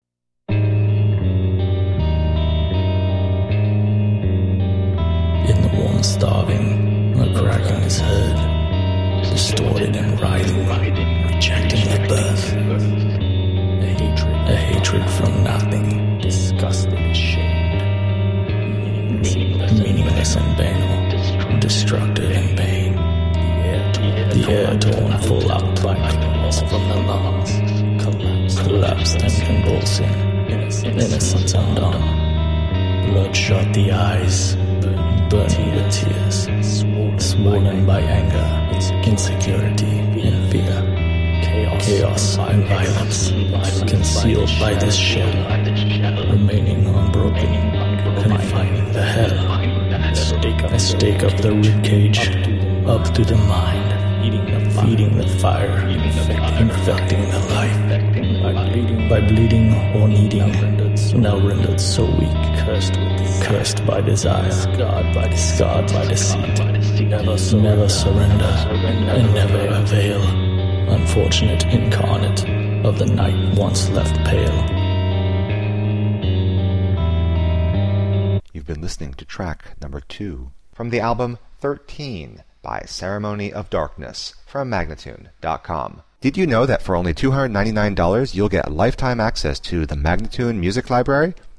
melodic heavy rock band